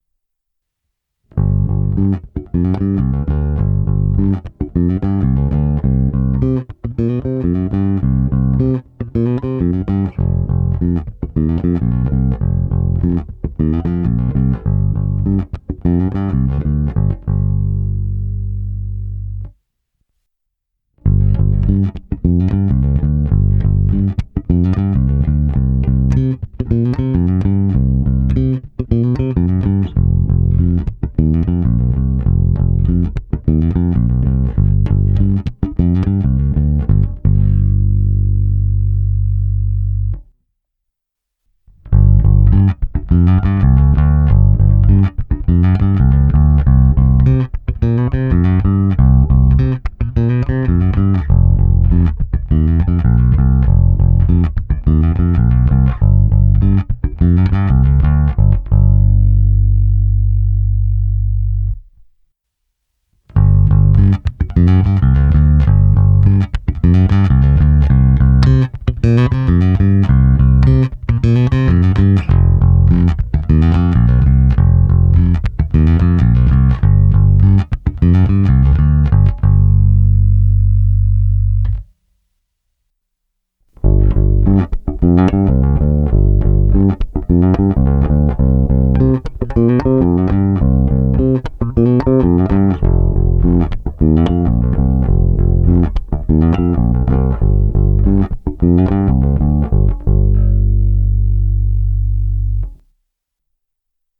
1) samotná baskytara
Pravda, GP-Lightstone Emperor z těch nahrávek celkem trčí.
Jsou to jen suché nahrávky, není to tedy nic moc extra vypovídajícího o tom, jak pak daný preamp funguje v kapele, ale i tak si třeba alespoň přibližný obrázek uděláte.